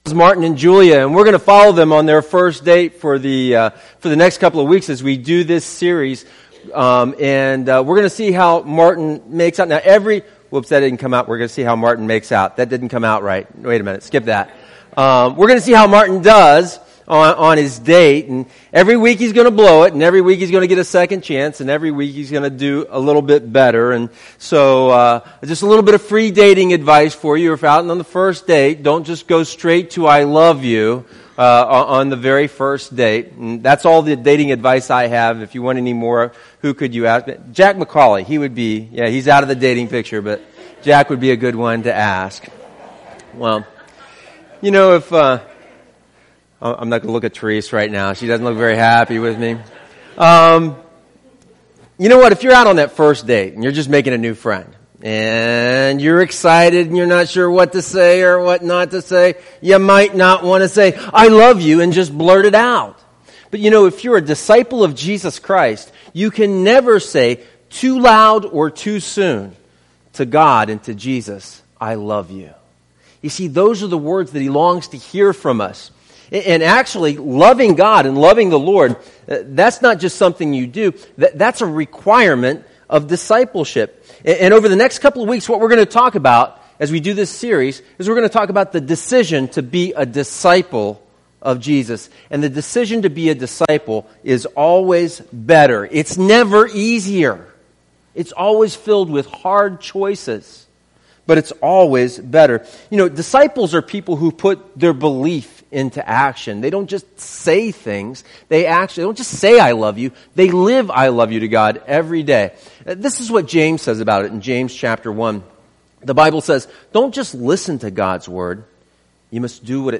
The introductory lesson in a series on being a better disciple.